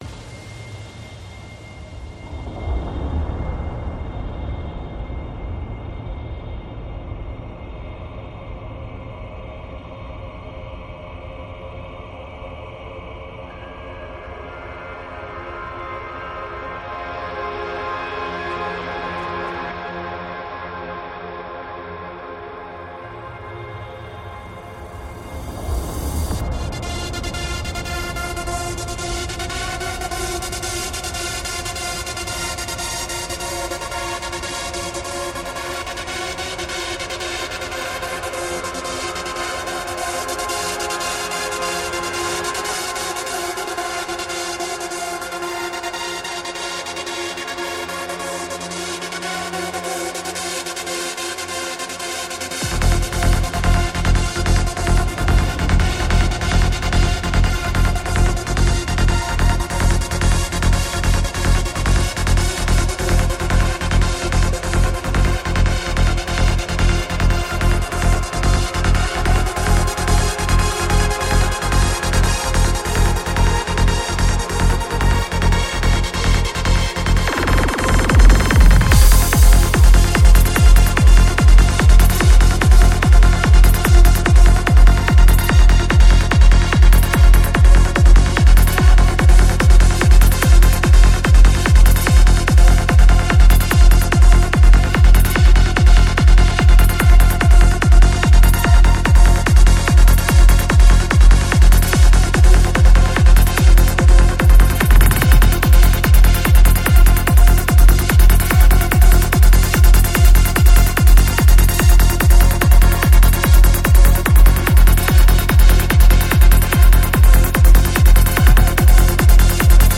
Жанр: electronics
Альбом: Psy-Trance